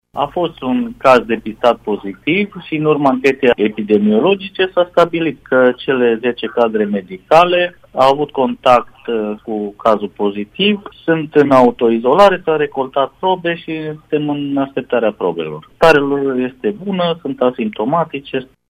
Directorul Direcţiei de Sănătate Publică Mureş, dr. Iuliu Moldovan, a declarat pentru Radio Tg Mureș că starea cadrelor medicale este bună.